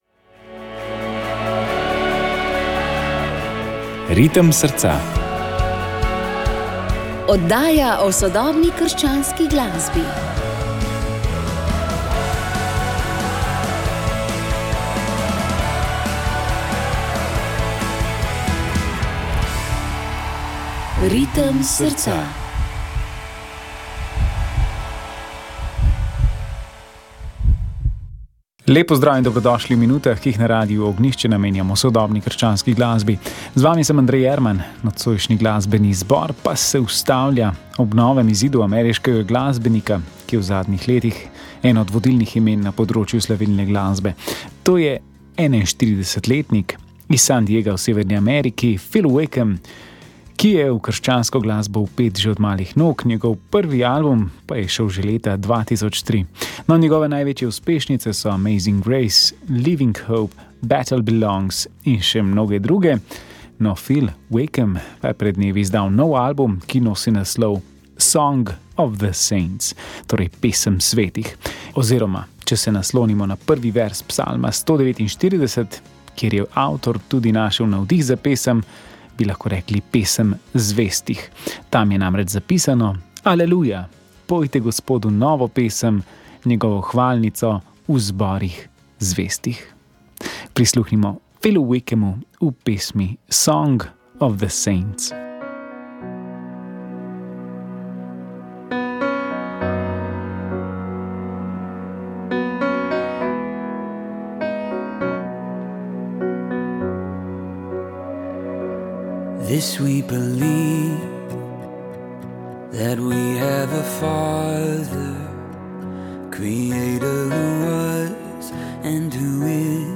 Poročali smo, kako gre romarjem, ki so ob 800-letnici Sončne pesmi vzeli v roke popotno palico in se peš odpravili iz Goričkega v Piran. Približno na polovici poti jih je naš mikrofon ujel pri kapucinih v Štepanji vasi.